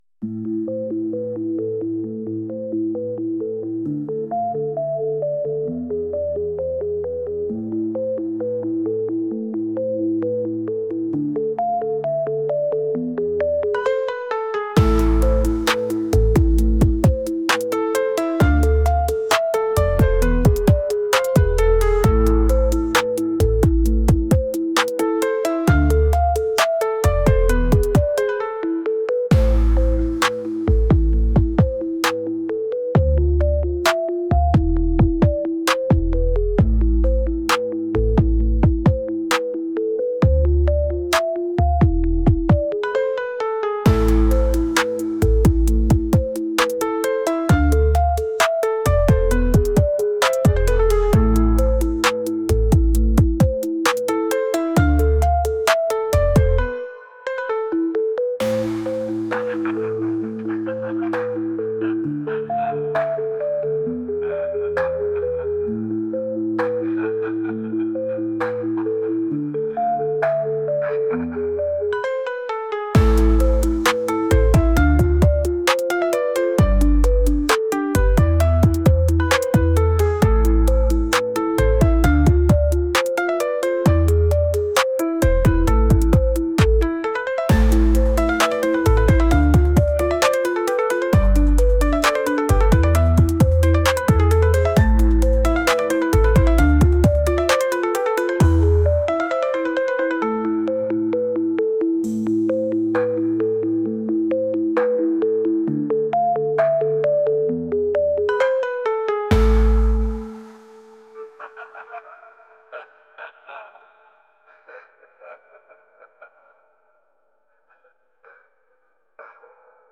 Инструментальное